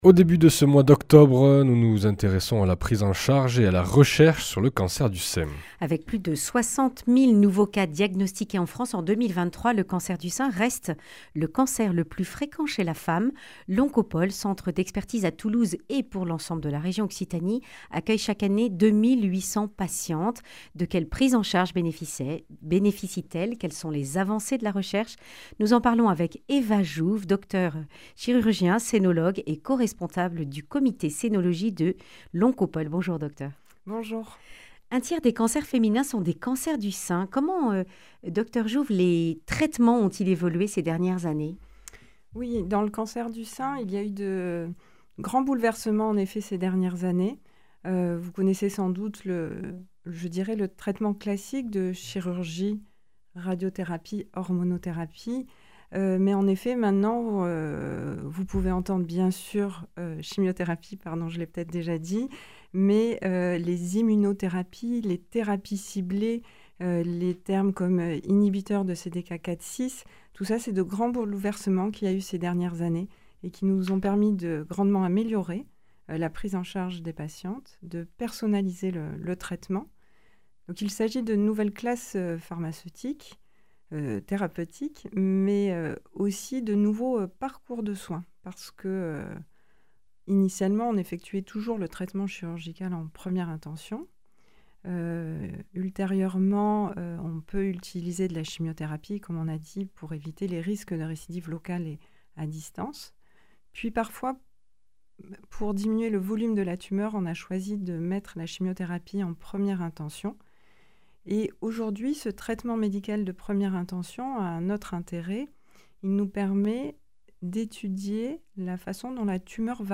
Accueil \ Emissions \ Information \ Régionale \ Le grand entretien \ Cancer du sein : quelles avancées dans les traitements et la recherche ?